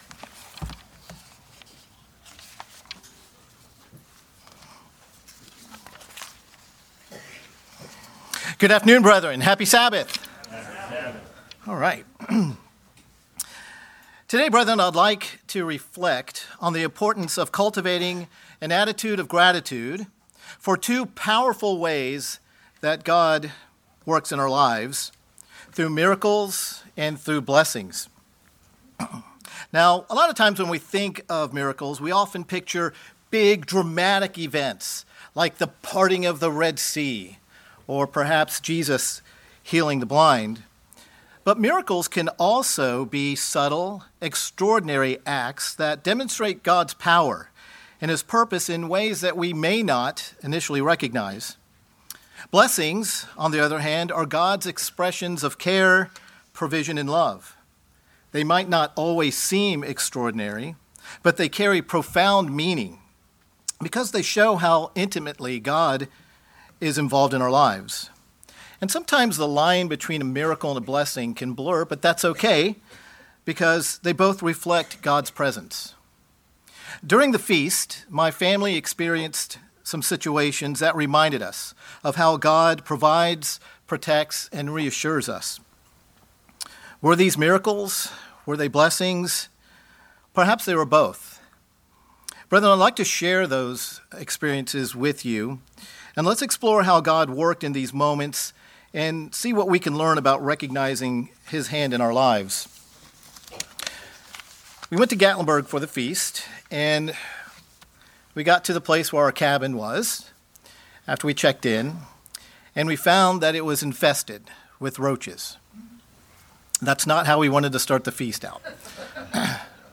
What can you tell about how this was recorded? Given in Cincinnati East, OH